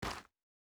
Shoe Step Gravel Hard C.wav